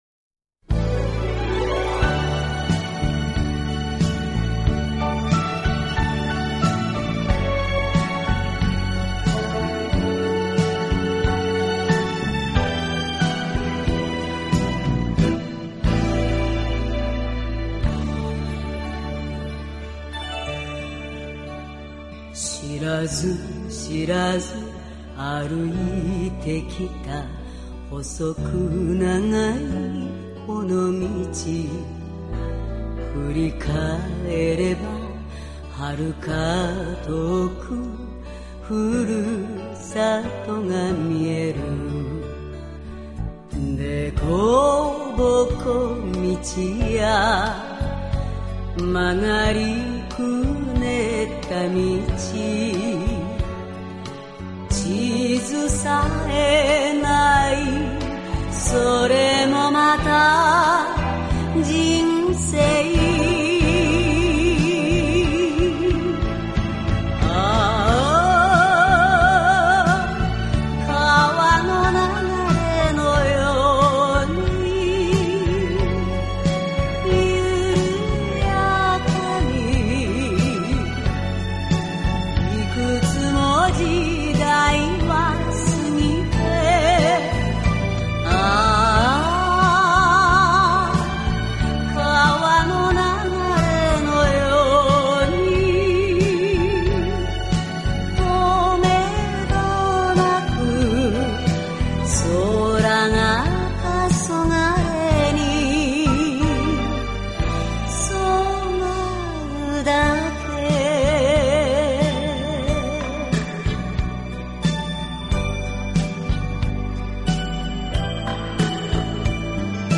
曲调很美，谢谢。